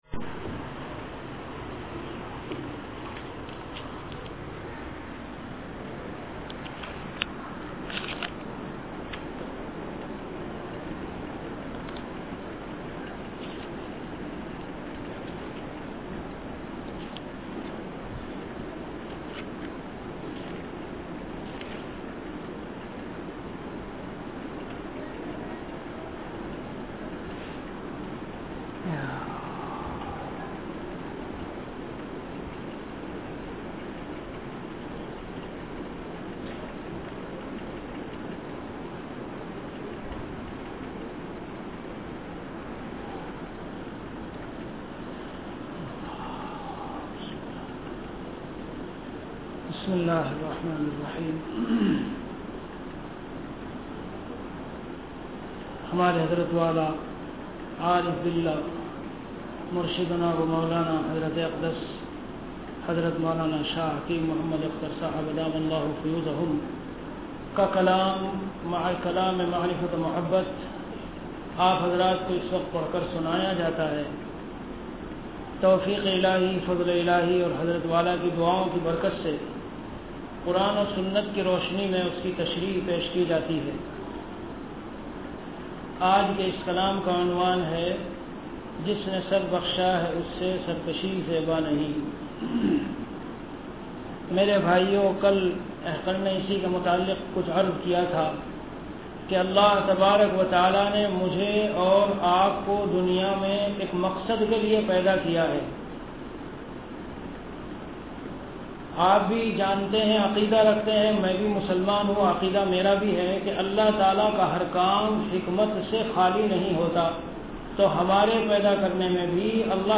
Delivered at Khanqah Imdadia Ashrafia.